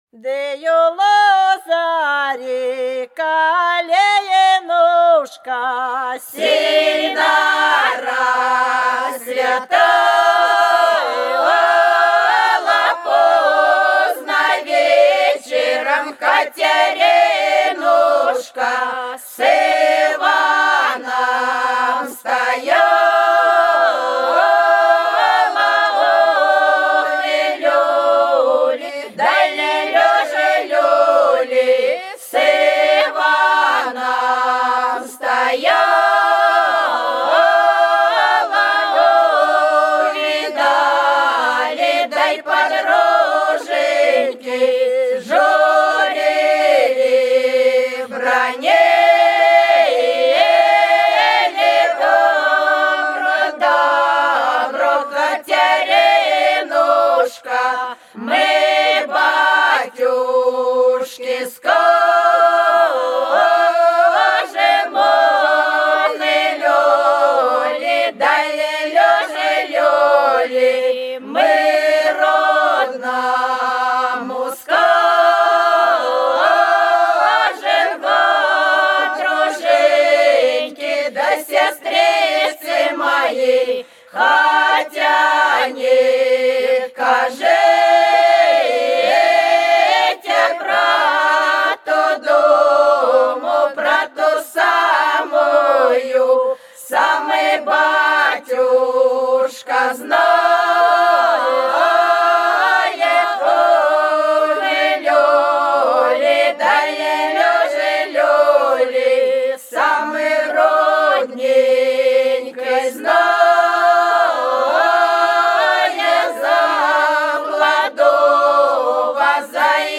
За речкою диво Да у лозари калинушка - свадебная (с. Русская Буйловка)
15_Да_у_лозари_калинушка_-_свадебная.mp3